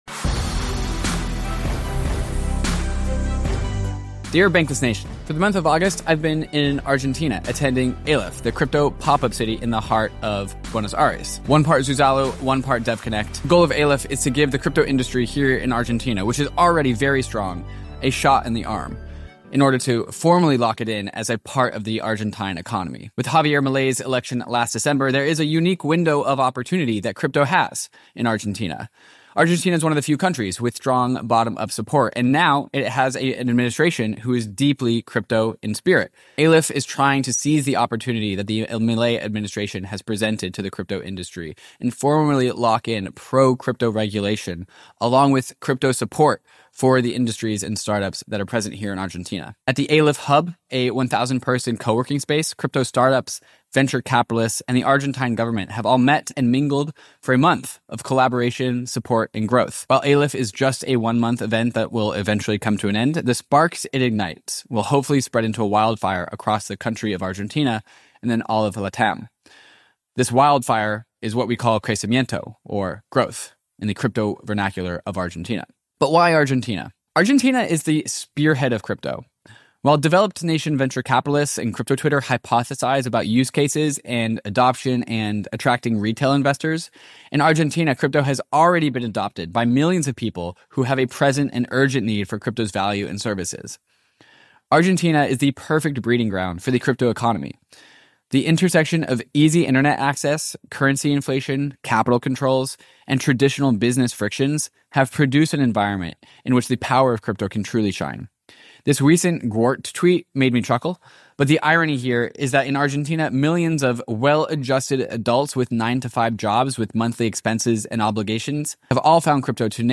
Today we're diving into the vibrant crypto ecosystem of Argentina as experienced at Aleph, a unique crypto pop-up city in Buenos Aires.